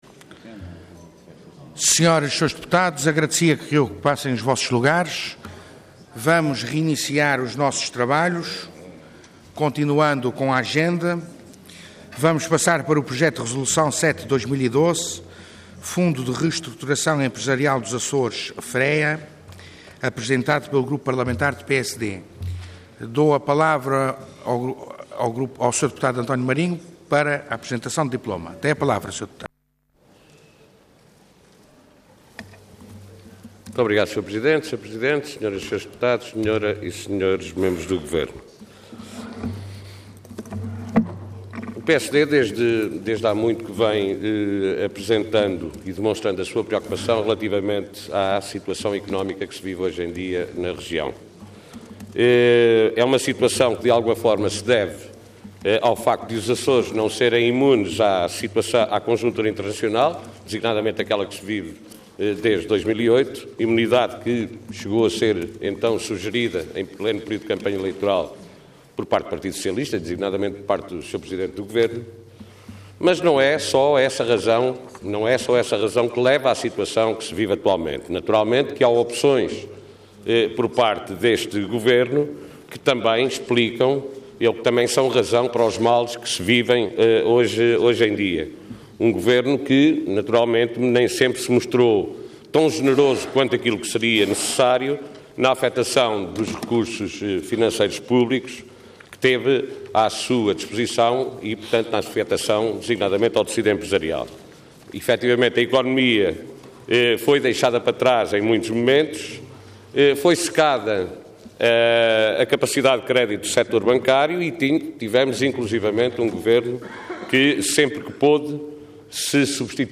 Intervenção Projeto de Resolução Orador António Marinho Cargo Deputado Entidade PSD